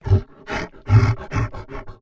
MONSTER_Exhausted_01_mono.wav